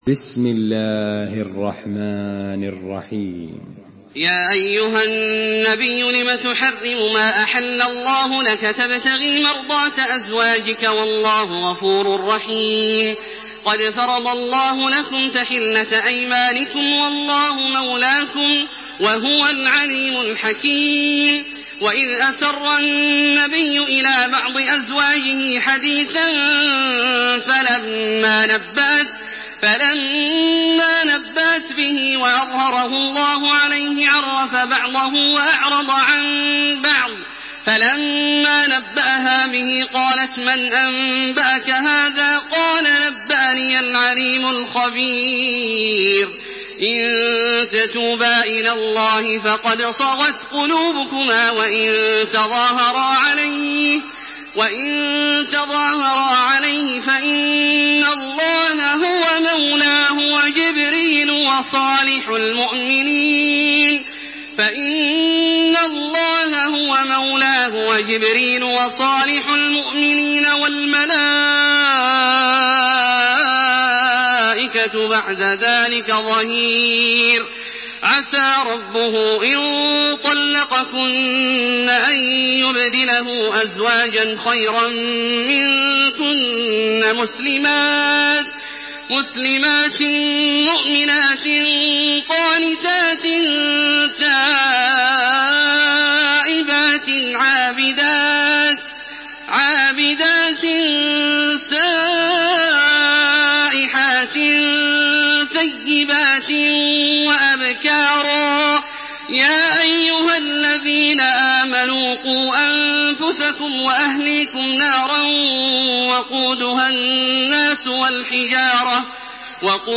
Surah التحريم MP3 by تراويح الحرم المكي 1428 in حفص عن عاصم narration.
مرتل